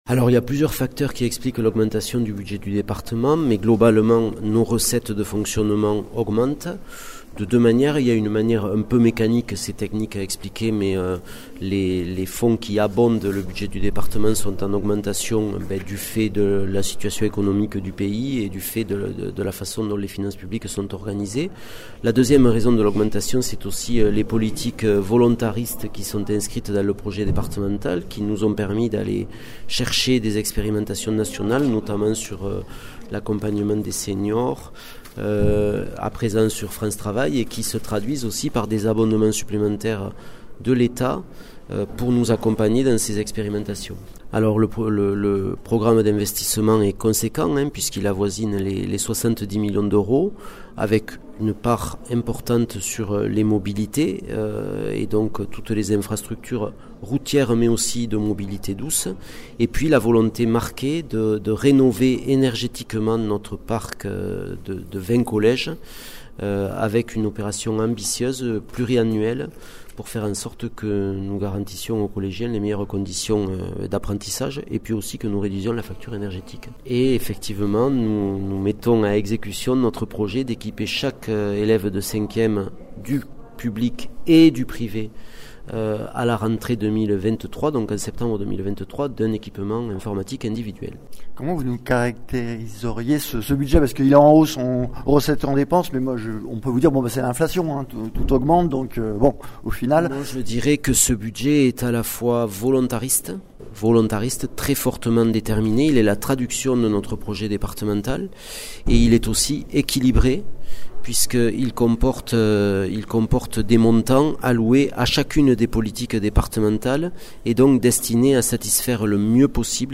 Interviews
Invité(s) : Arnaud Viala, Président du conseil départemental de l ’Aveyron